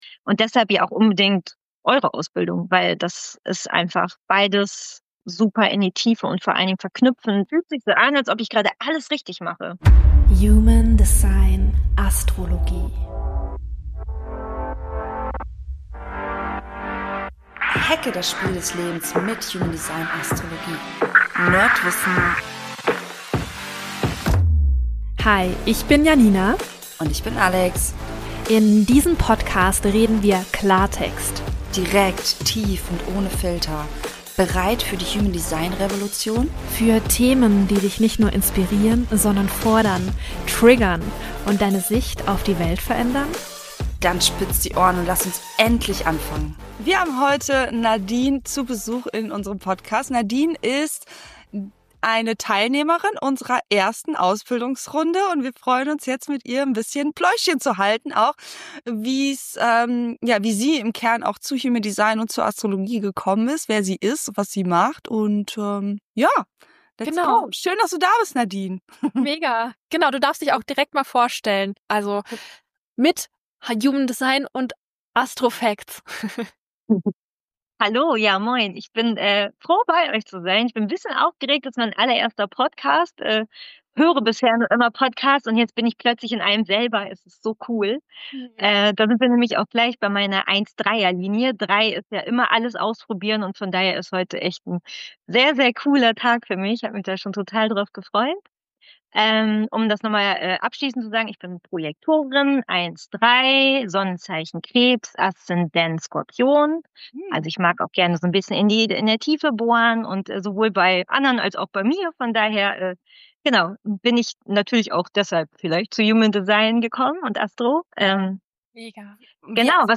Beschreibung vor 1 Jahr Wir haben mit ihr über ihre ganz persönliche Reise gesprochen, über ihre Begeisterung für die Tools, über ihren Weg zu uns – und was sich für sie durch die Ausbildung verändert hat. Ohne Druck, ohne Plan – einfach ein ehrliches, schönes Gespräch über all das, was Human Design & Astrologie im Leben bewirken können.